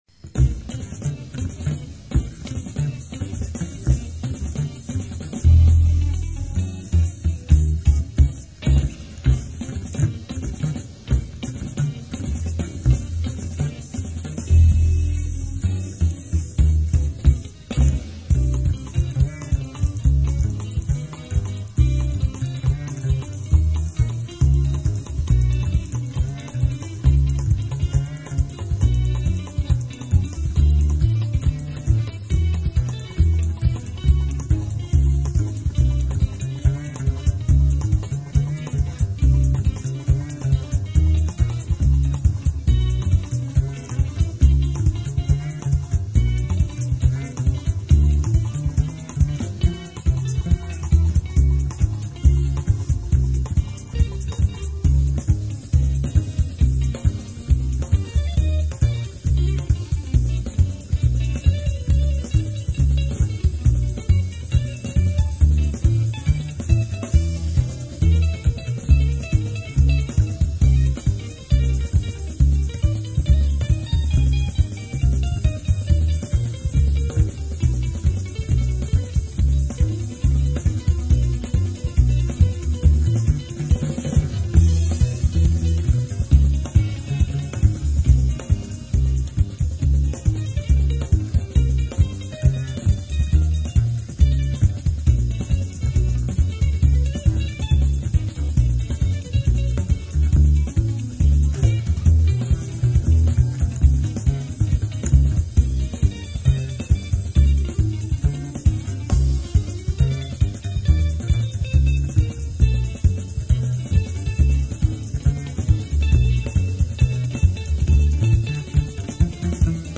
обучение игре на гитаре, контрабасе